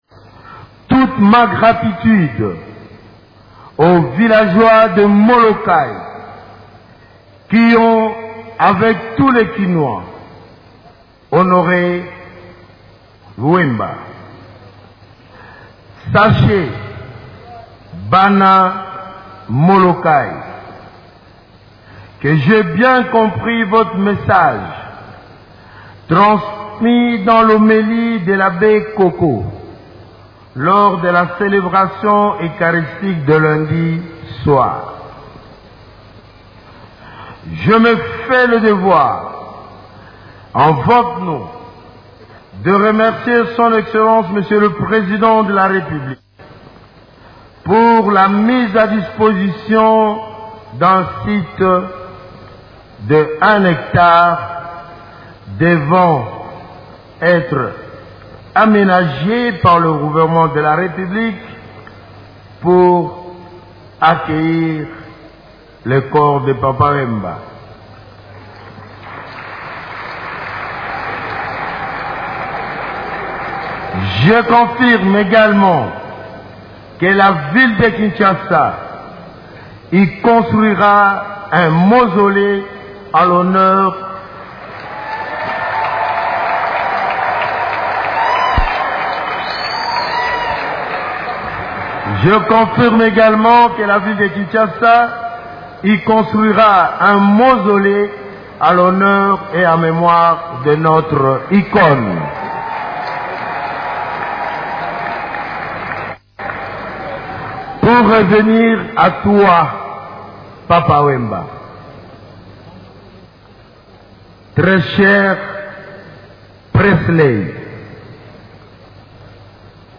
Le gouverneur de la ville de Kinshasa, André Kimbuta, l’a annoncé lors de son oraison funèbre prononcée à la cathédrale Notre Dame du Congo où une messe a été dite.
messe_papa_wemba_2.1.mp3